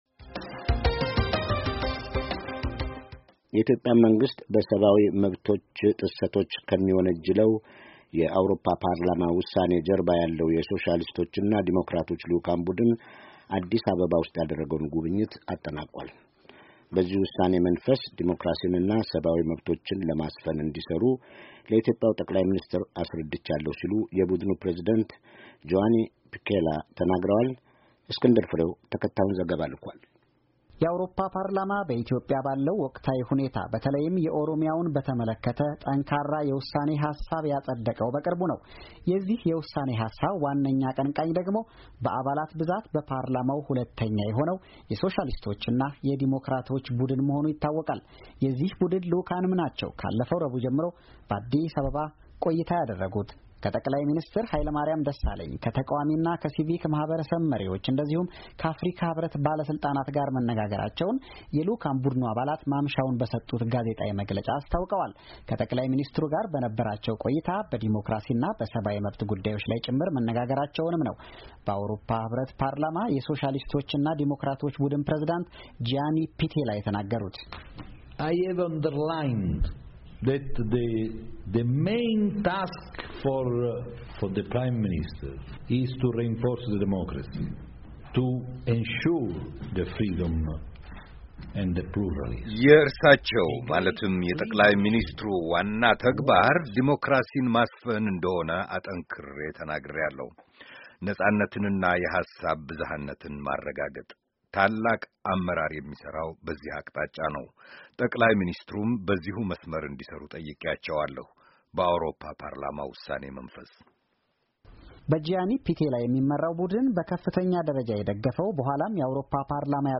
ከአዲስ አበባ የላከውን ዘገባ